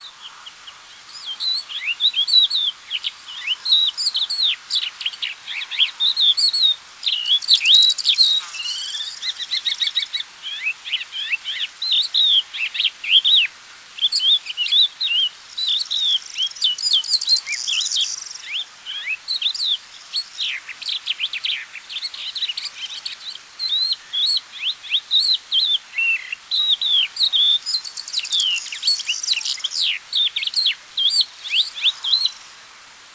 GALERIDA CRISTATA - CRESTED LARK - CAPPELLACCIA